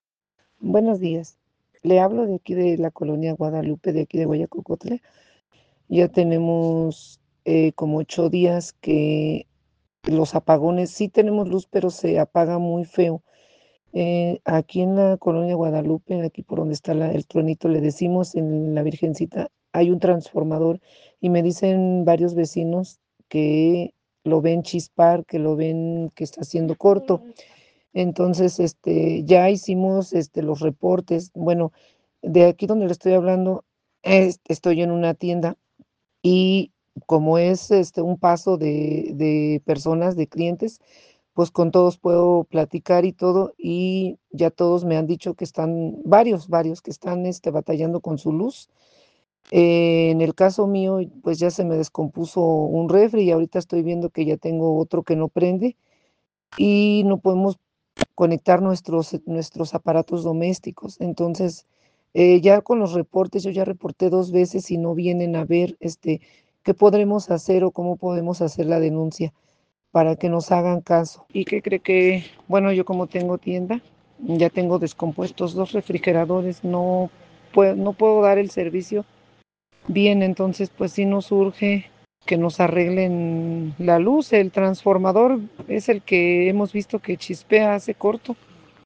Compartimos el testimonio de una vecina de la cabecera municipal.